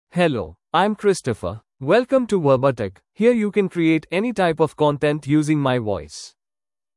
MaleEnglish (India)
Christopher is a male AI voice for English (India).
Voice sample
Male
Christopher delivers clear pronunciation with authentic India English intonation, making your content sound professionally produced.